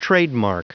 Prononciation du mot trademark en anglais (fichier audio)
Prononciation du mot : trademark